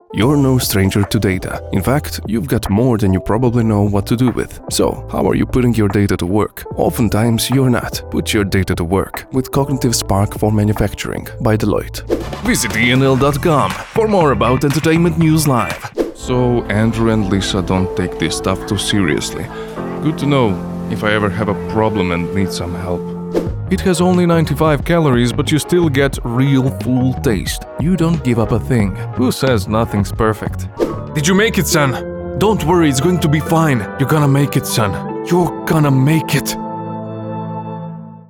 Male
20s, 30s, 40s
Croatian Demo Reel.mp3
Microphone: Neumann TLM103
Audio equipment: Sound booth, Audient iD14, mic stand, metal pop filter